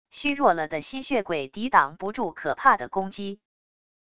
虚弱了的吸血鬼抵挡不住可怕的攻击wav音频